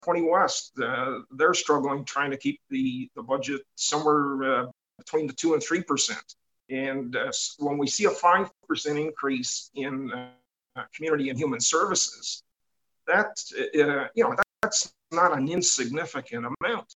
Hastings County Community and Human Service meeting, March 9, 2022 (Screengrab)
Committee member, Councillor Bill Sandison, objected to the increase saying it will create undue hardship for local residents.